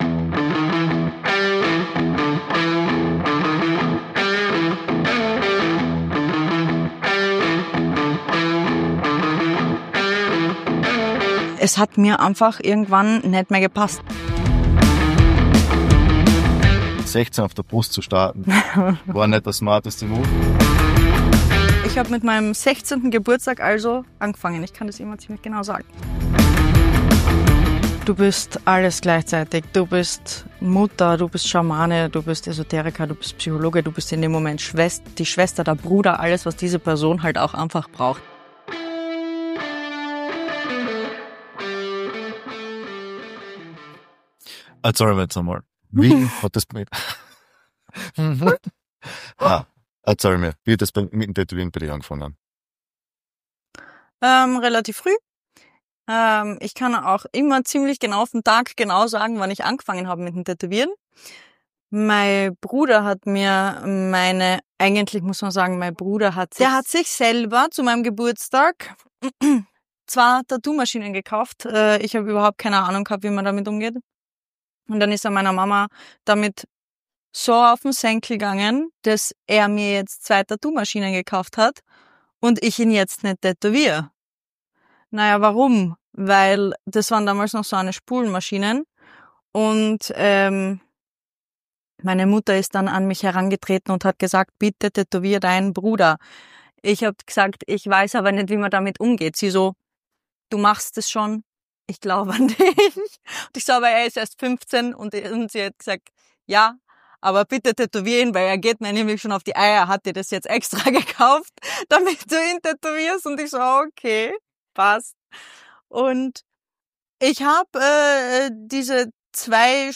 Willkommen zur allerersten Folge von dear skin, dem Podcast direkt aus dem Tattoo-Studio. In dieser Episode tauchen die beiden Hosts in die bunte Welt der Tattoos ein.